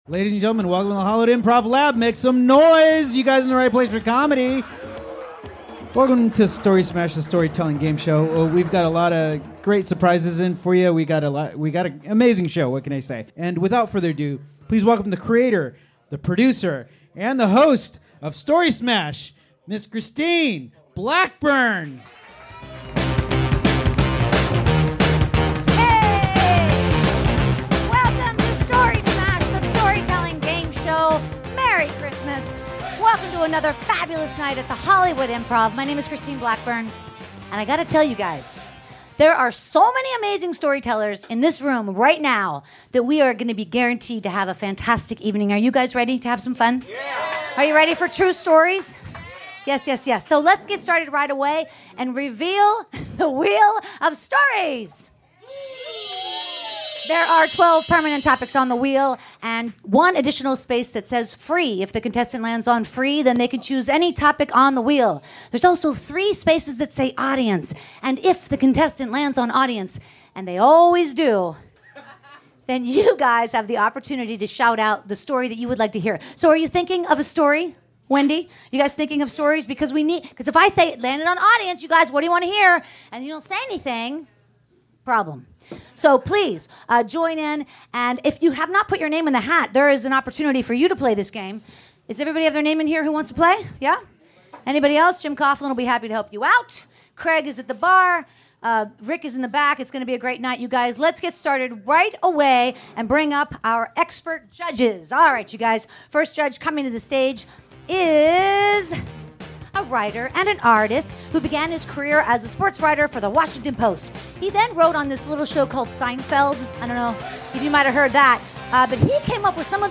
Story Smash the Storytelling Gameshow LIVE at the Hollywood Improv!
Recorded December, 2018 at The Hollywood Improv.